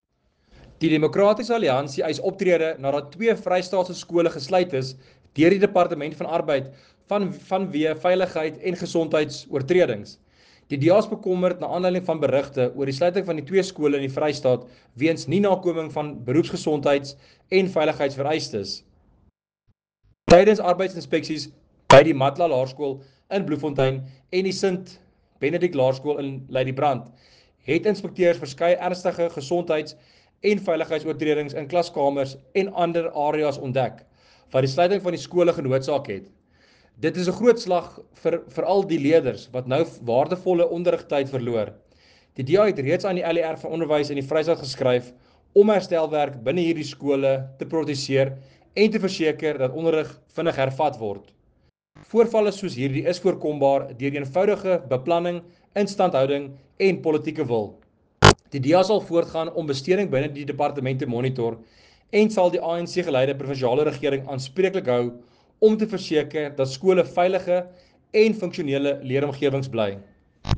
Afrikaans soundbite by Werner Pretorius MPL.